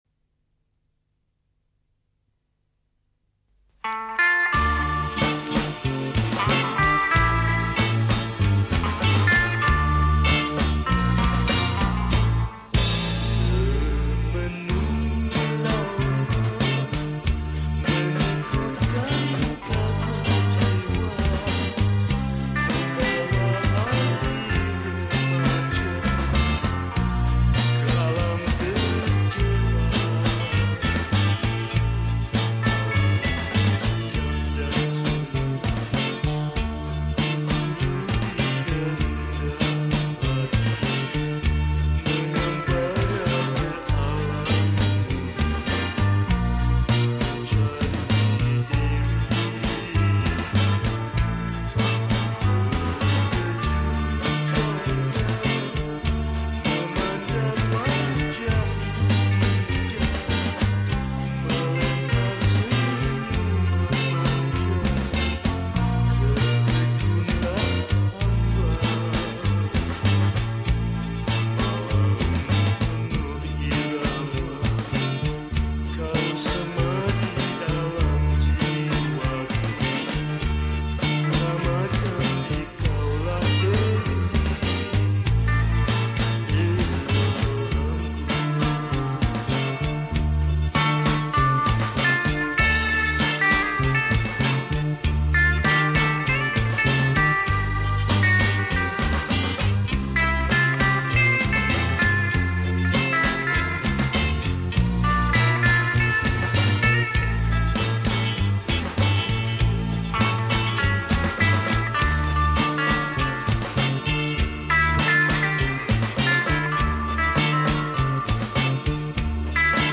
Malay Songs